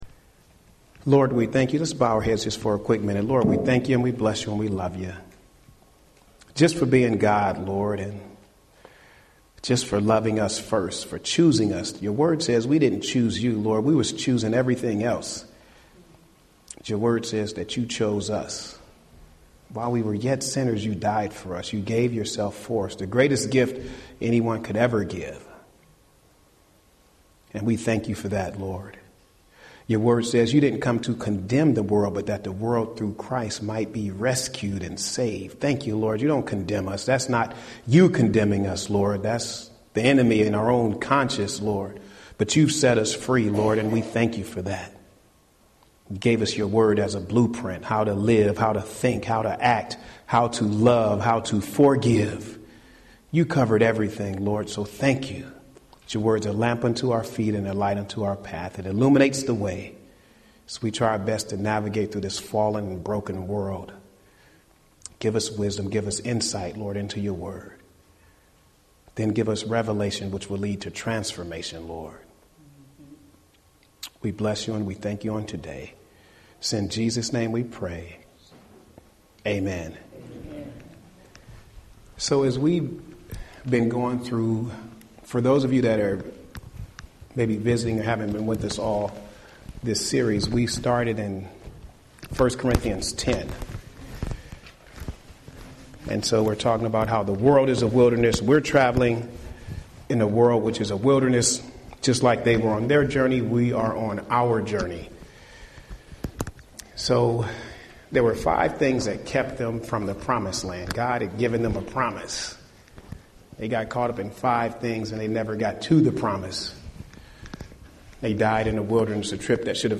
Home › Sermons › Is God Enough?